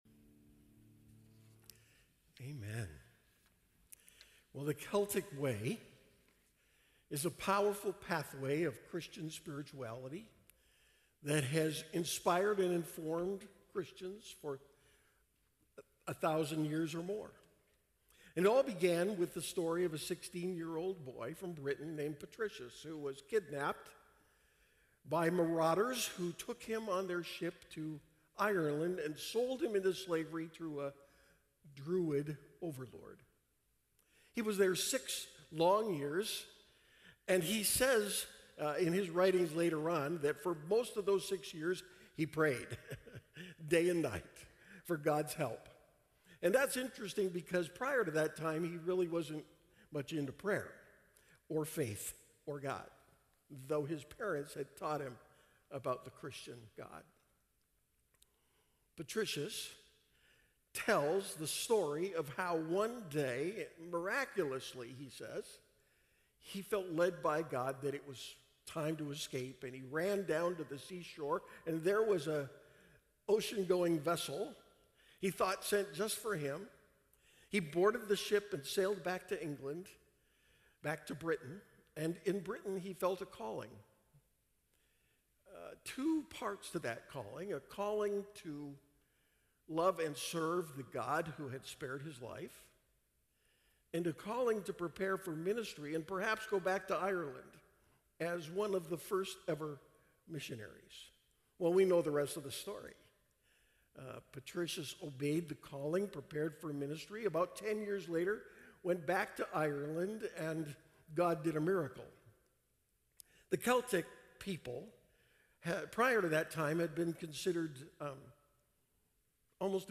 Celtic-Way-Sermon-Audio_01.mp3